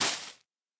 Minecraft Version Minecraft Version latest Latest Release | Latest Snapshot latest / assets / minecraft / sounds / block / sponge / step1.ogg Compare With Compare With Latest Release | Latest Snapshot
step1.ogg